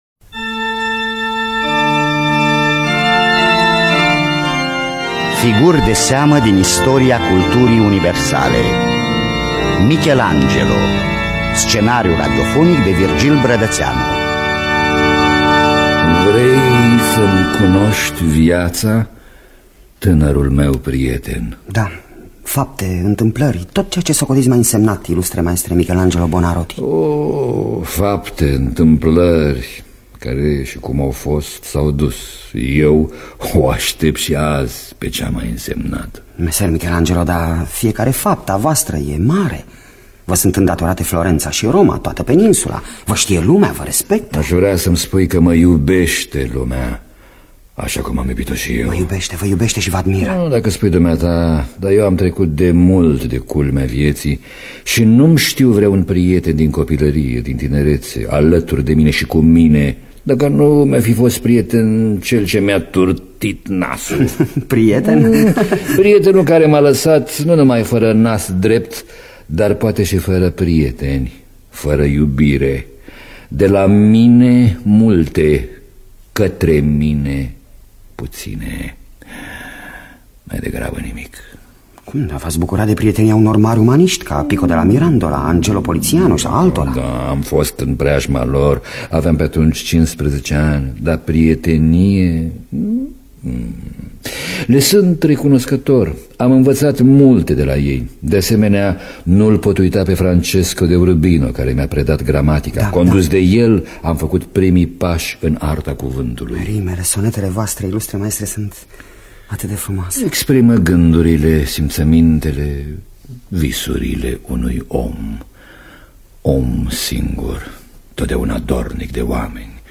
Biografii, memorii: Michelangelo Buonarroti. Scenariu radiofonic de Virgil Brădățeanu.